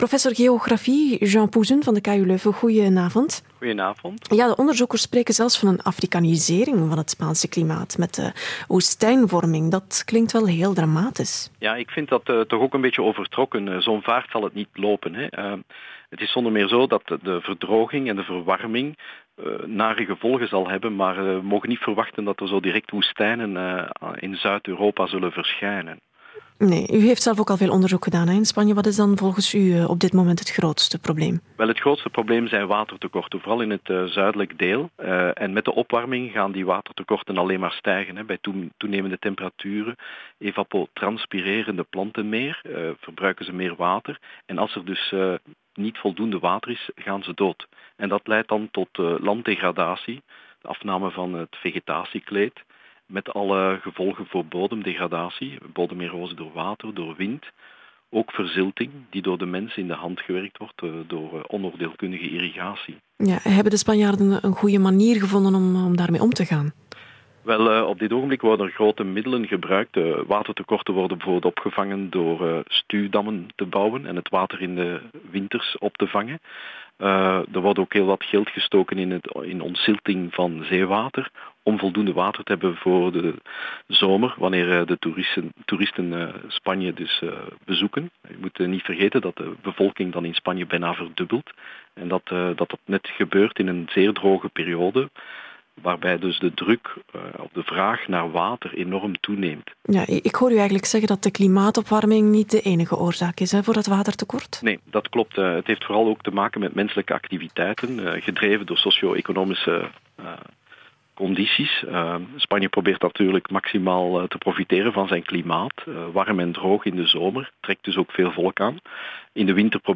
The following interviews were broadcast on Dutch, Belgian and Portuguese radio.